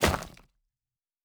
Stone 05.wav